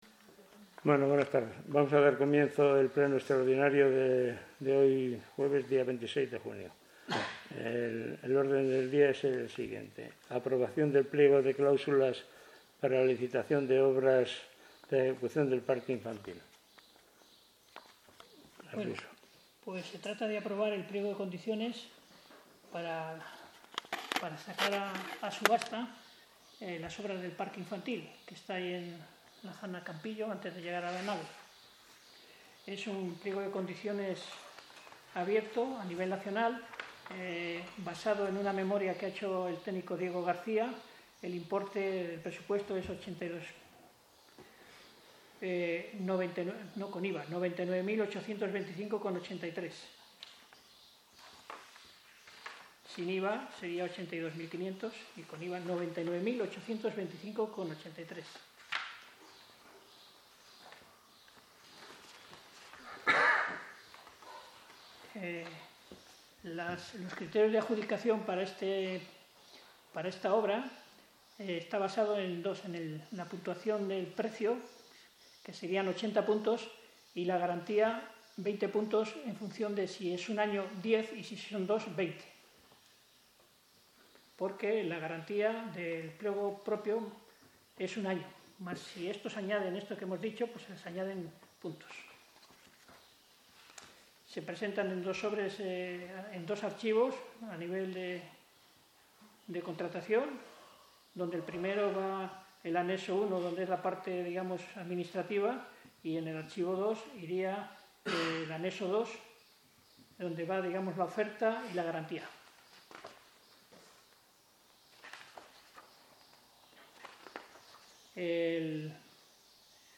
.- Convocatoria del pleno extraordinario para el 26/06/2025 .- Orden del Día .- Audio del Pleno celebrado el 26/06/2025